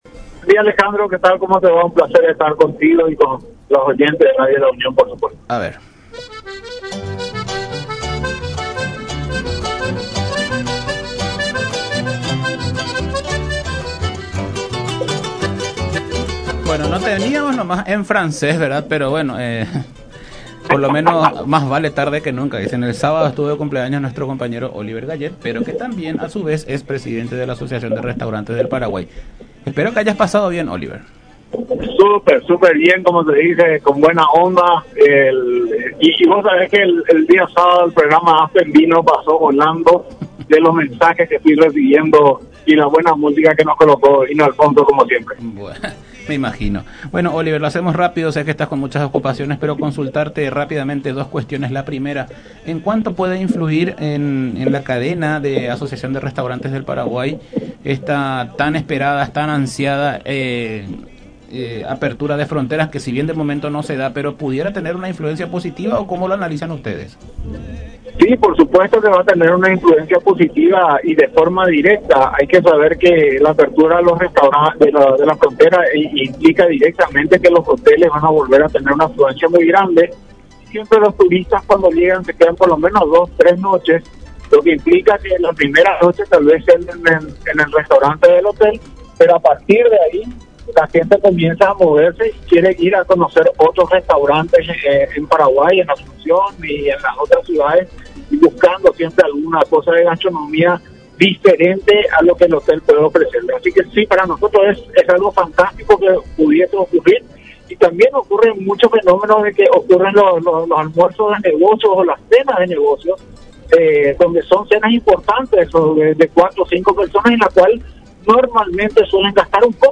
en diálogo con Todas Las Voces por La Unión